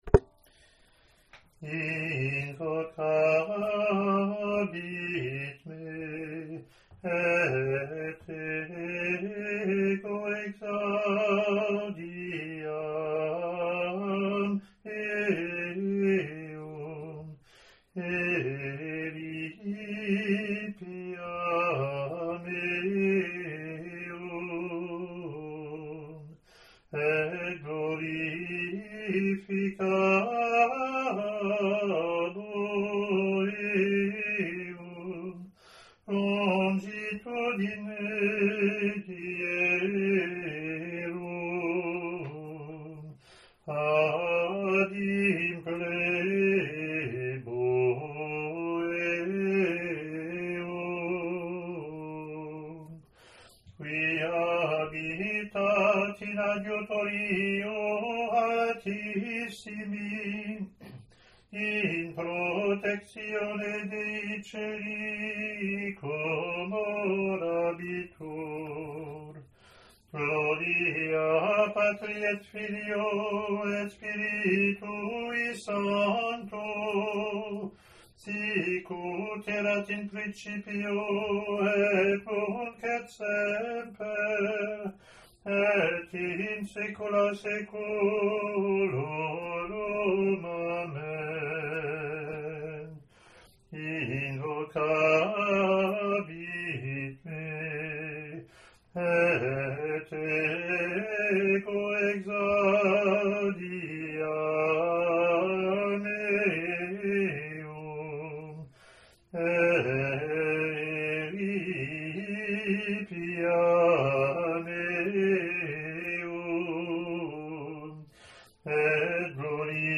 Latin antiphon)
lt01-introit-gm.mp3